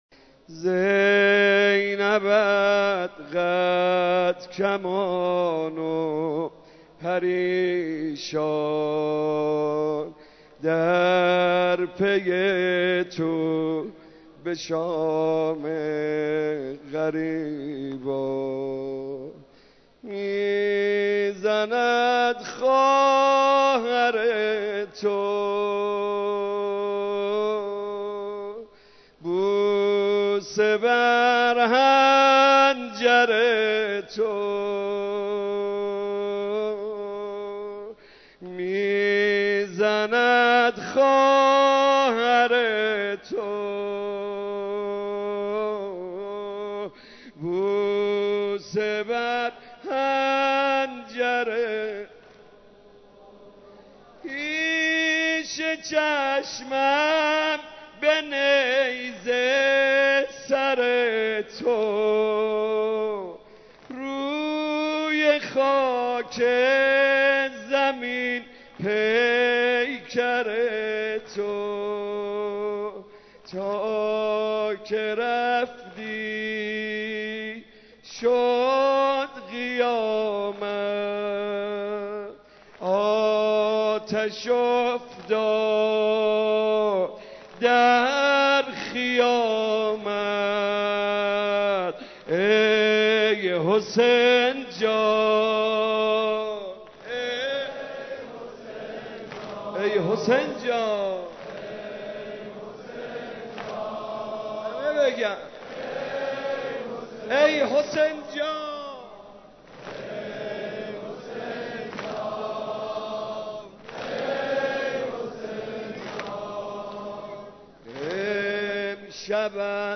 مراسم عزاداری شام غریبان حضرت اباعبدالله الحسین علیه‌السلام
مداح